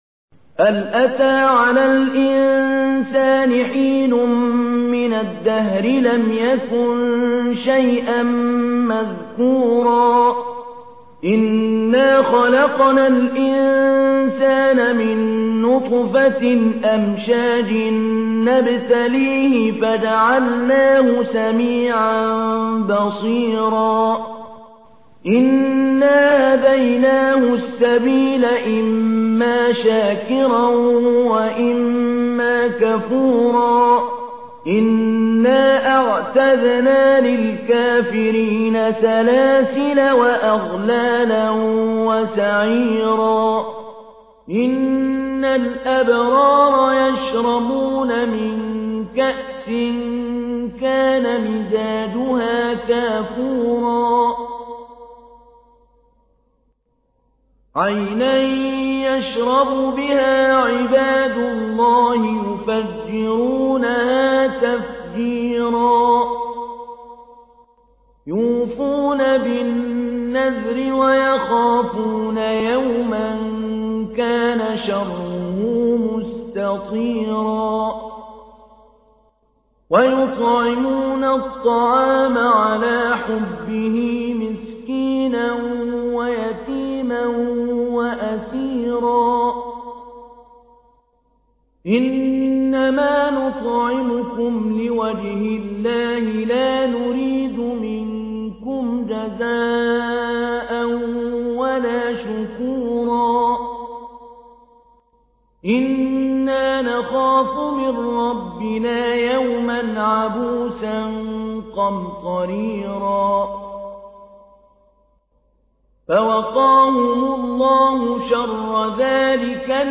تحميل : 76. سورة الإنسان / القارئ محمود علي البنا / القرآن الكريم / موقع يا حسين